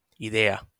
wymowa:
IPA[i.ˈðe.a]